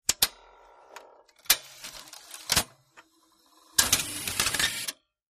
3 /4" Video tape deck tape loads and plays. Tape Loading Transport Engage Eject Tape